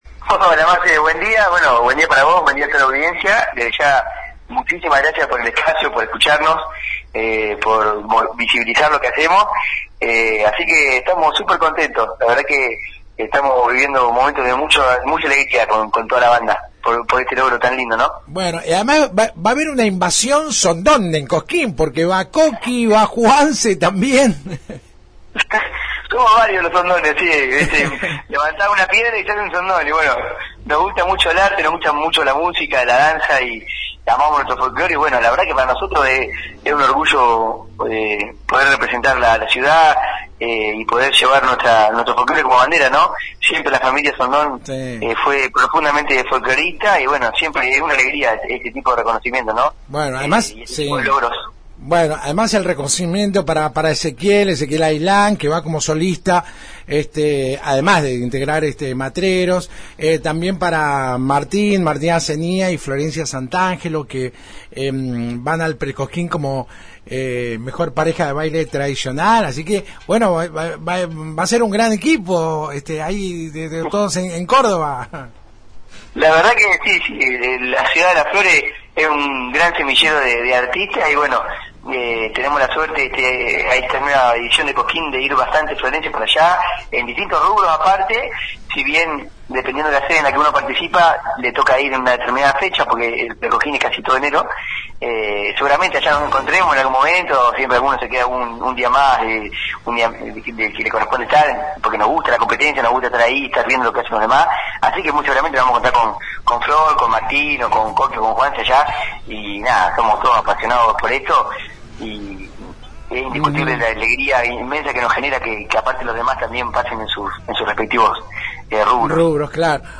Como es sabido, Las Flores tendrá sus representantes en el Pre-Cosquín del año próximo, un evento que se realizará en los primeros días de enero en la provincia de Córdoba. La 91.5 habló este miércoles con